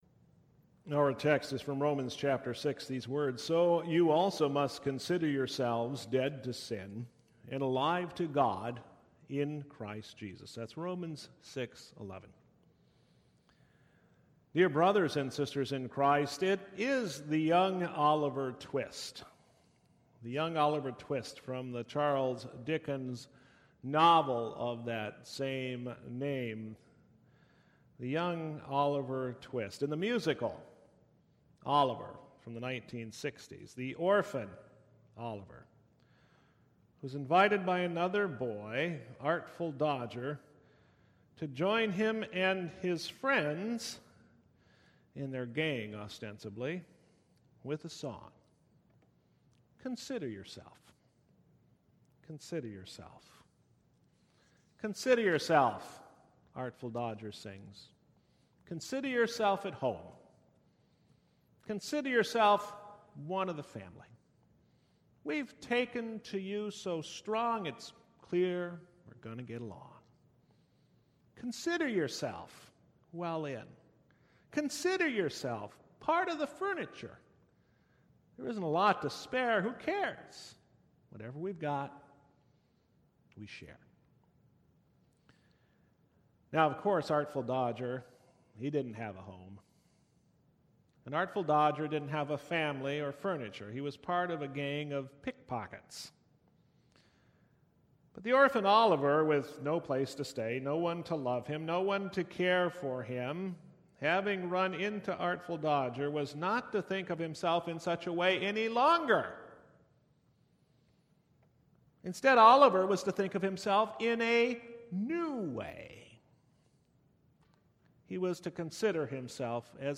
- Prince of Peace Lutheran Church